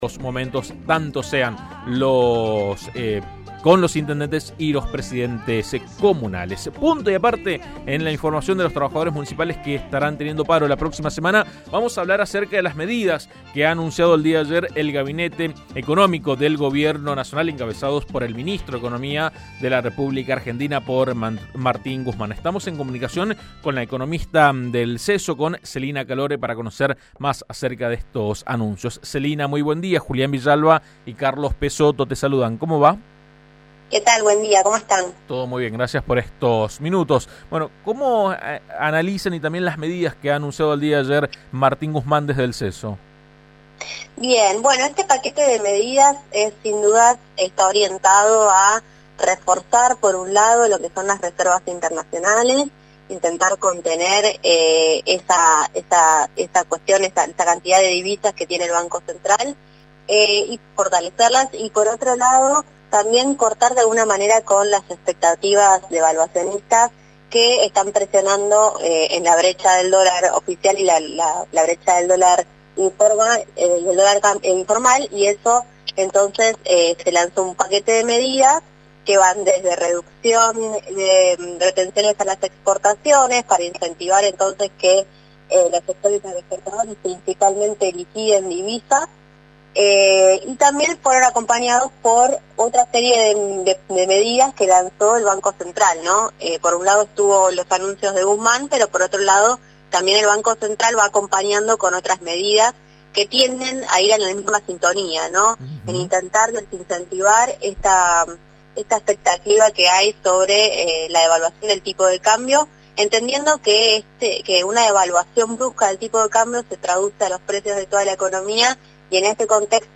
analizó las medidas en diálogo con AM 1330.